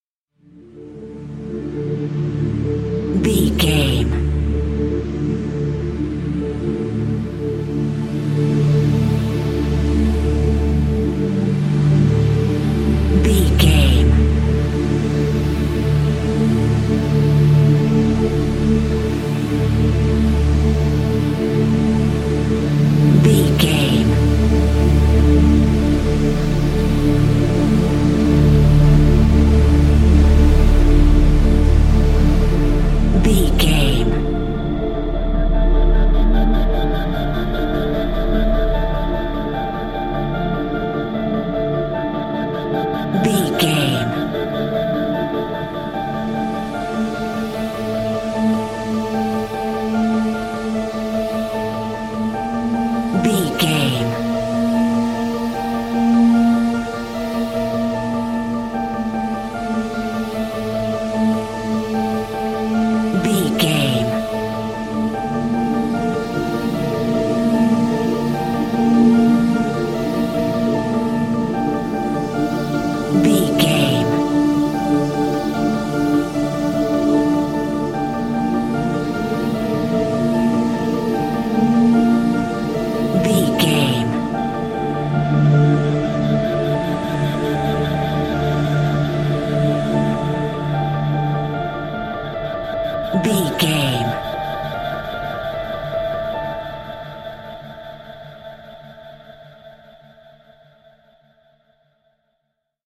Thriller
Aeolian/Minor
Slow
drum machine
synthesiser
electric piano